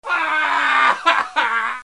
Suono effetto - wav Waah
Voce umana che urla "Waah ! Ah ah !"